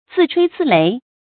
注音：ㄗㄧˋ ㄔㄨㄟ ㄗㄧˋ ㄌㄟˊ
自吹自擂的讀法